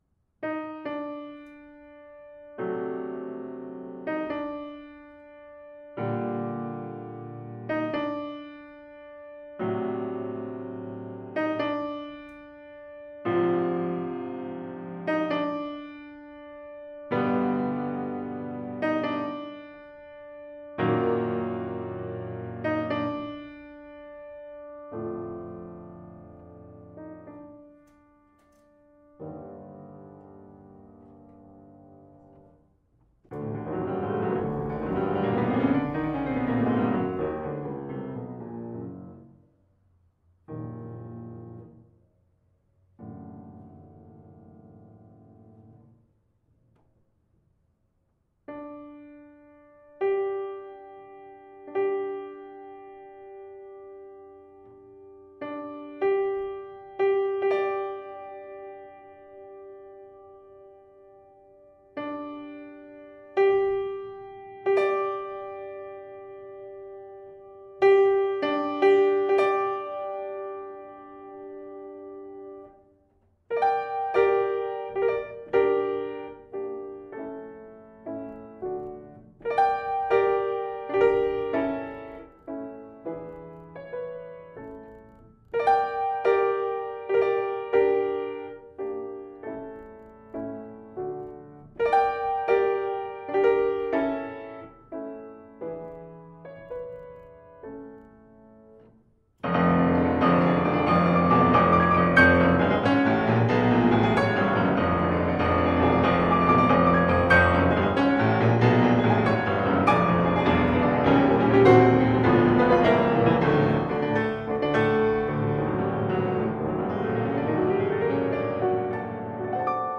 No parts available for this pieces as it is for solo piano.
Piano  (View more Advanced Piano Music)
Classical (View more Classical Piano Music)